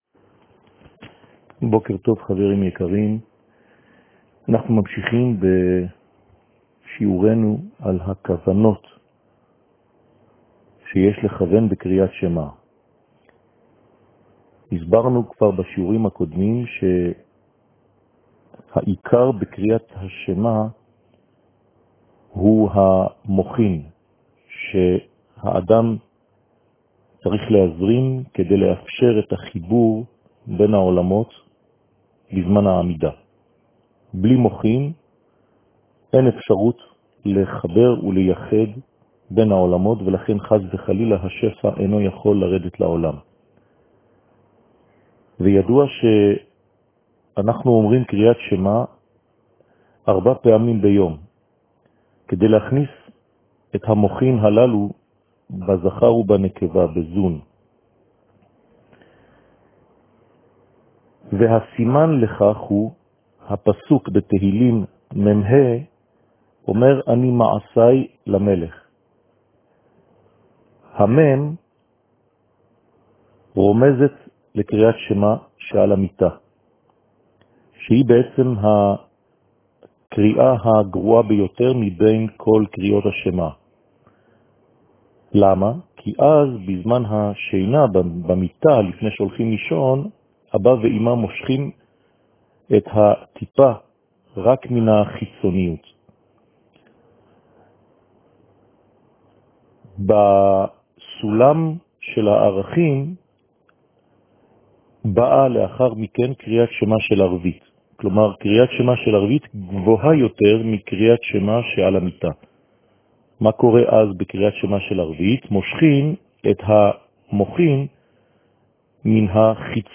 שיעור מ 05 יולי 2020